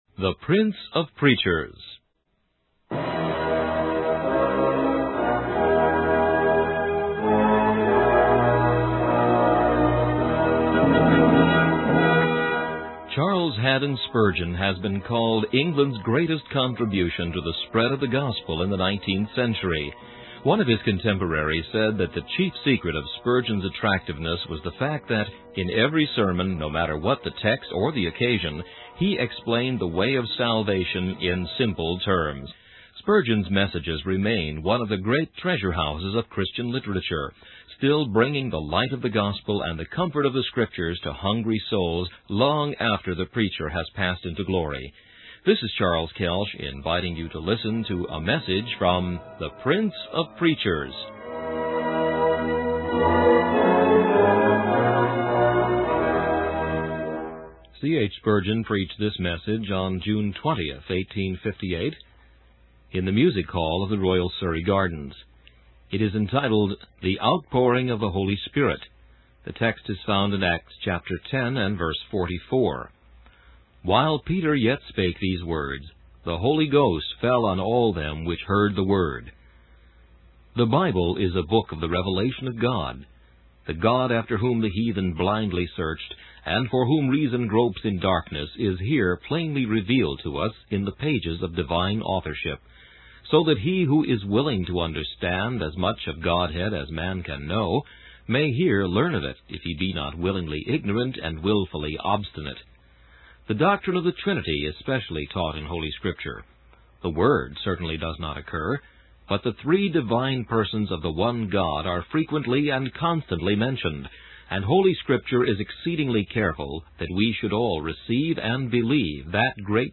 In this sermon, the preacher emphasizes the power of the Holy Spirit to bring about conversion and salvation.